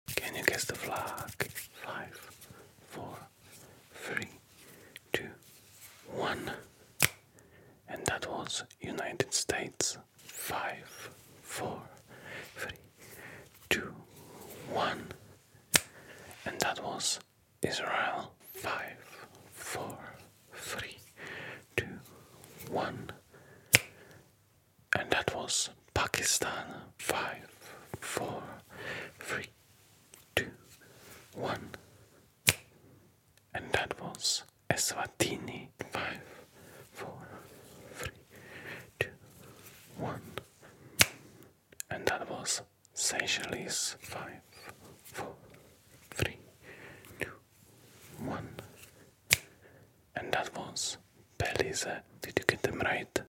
ASMR | Guess the Flag sound effects free download
ASMR | Guess the Flag (countdown)